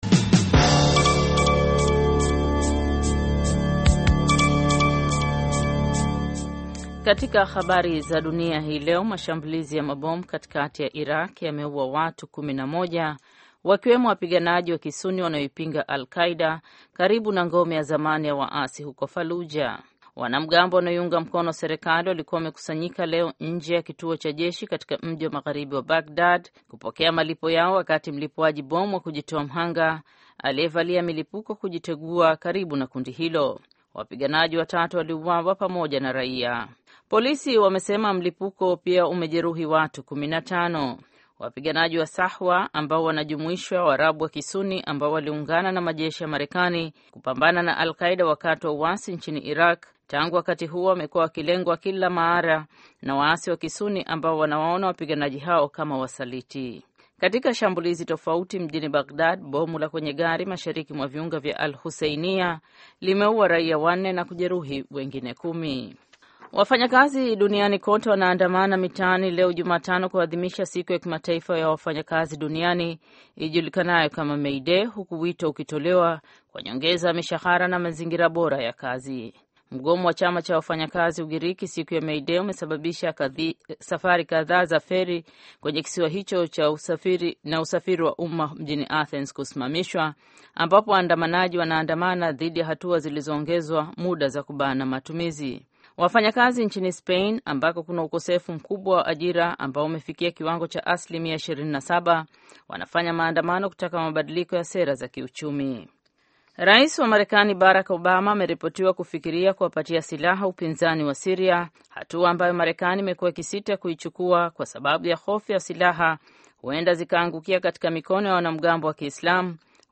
News.